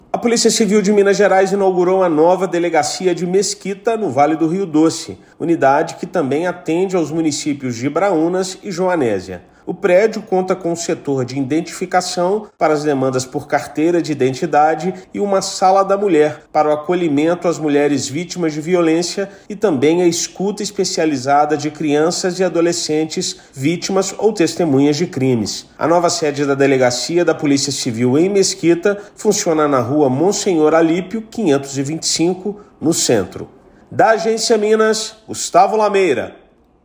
[RÁDIO] Polícia Civil inaugura nova sede de delegacia em Mesquita
Unidade irá contar com Setor de Identificação e Sala da Mulher. Ouça matéria de rádio.